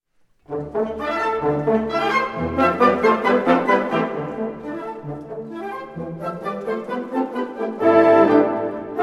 Bezetting Ha (harmonieorkest)